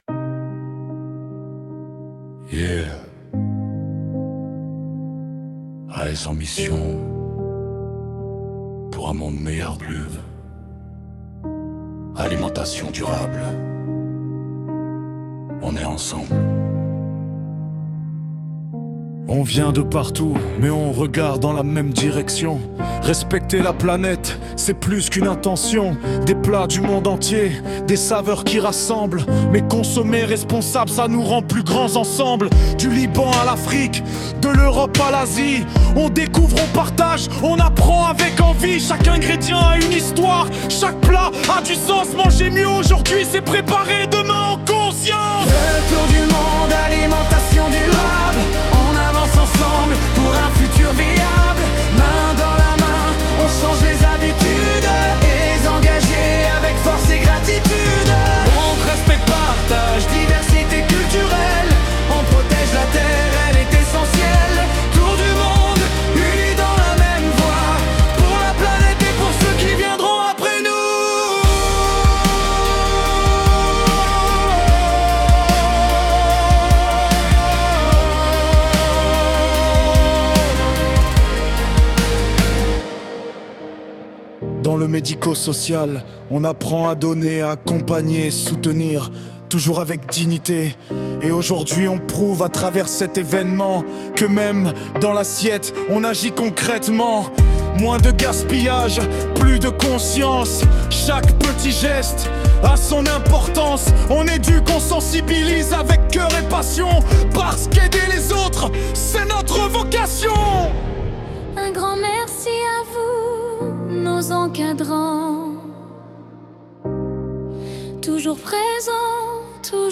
Dans une démarche créative et moderne, les stagiaires ont enrichi leur projet en réalisant une chanson à l’aide de l’intelligence artificielle.